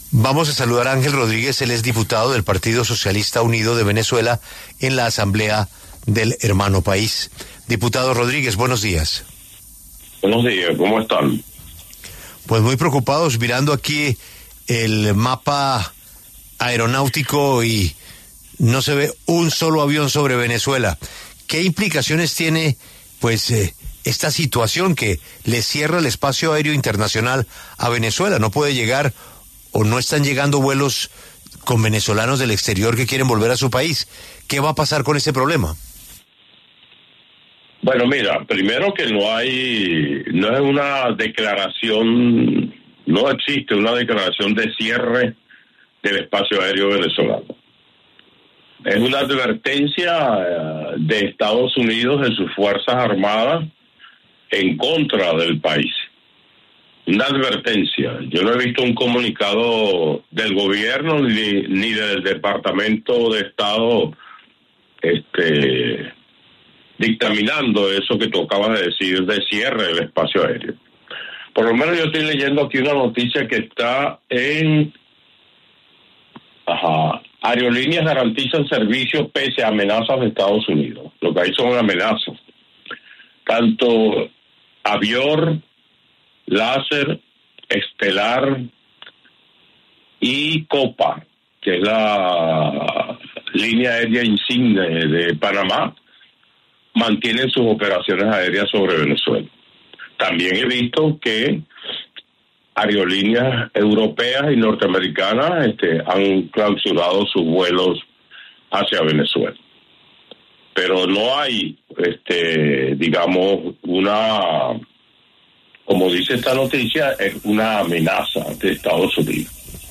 Ángel Rodríguez, diputado del Partido Socialista Unido de Venezuela (PSUV), conversó con La W sobre el anuncio de Estados Unidos de una posible operación militar en espacio aéreo venezolano.